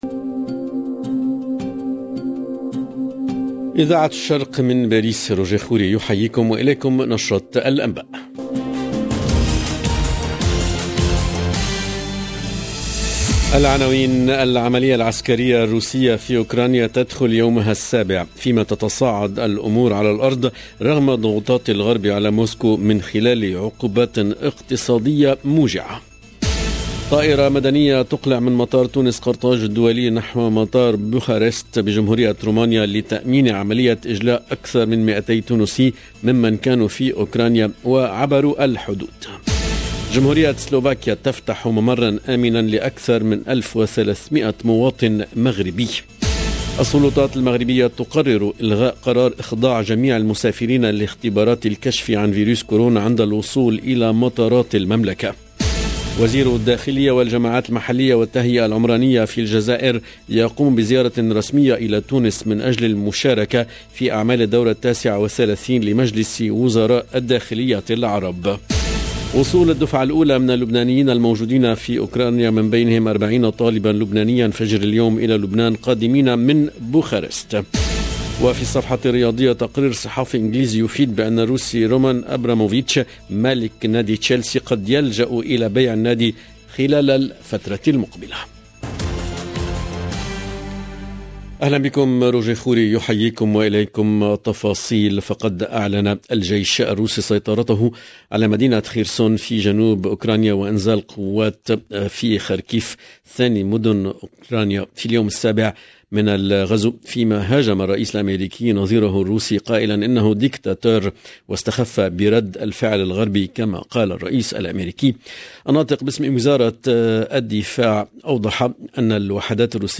LE JOURNAL DE MIDI 30 EN LANGUE ARABE DU 2/03/22